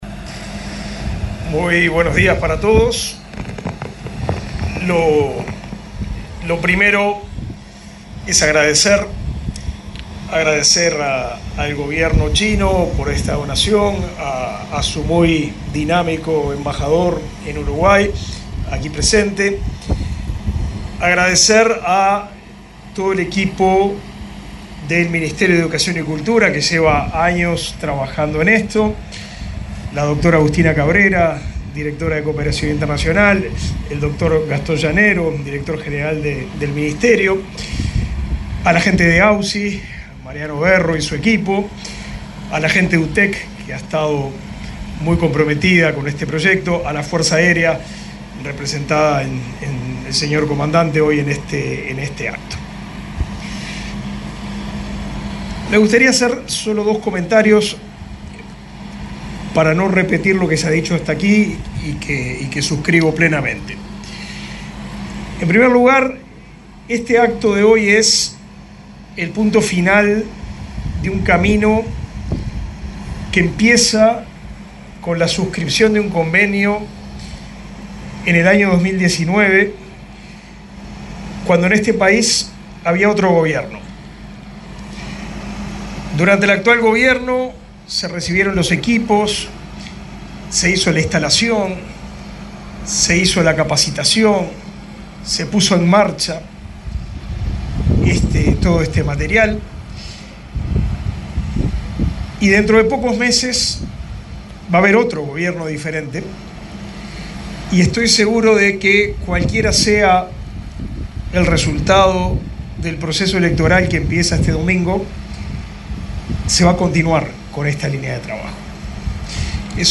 El ministro de Educación y Cultura, Pablo da Silveira, se expresó, este martes 22 en el aeropuerto de Carrasco, durante la presentación de un sistema